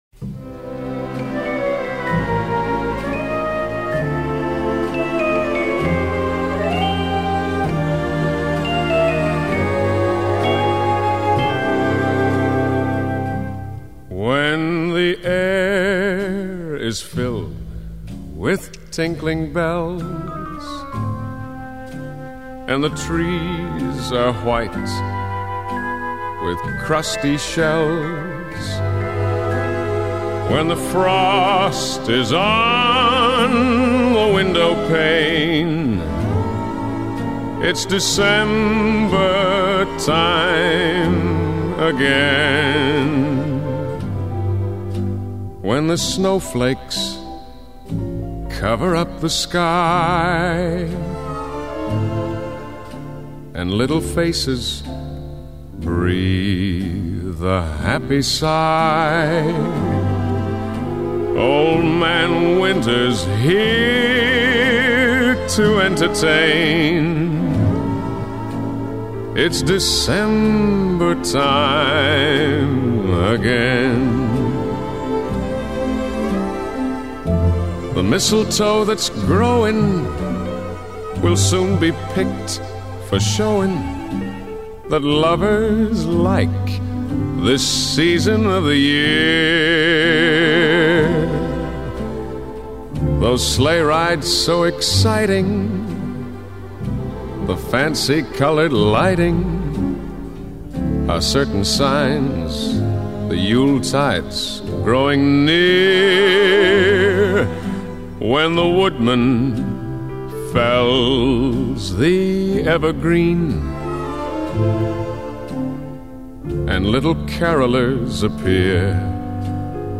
It’s smooth and easy, like a cold, quiet December night.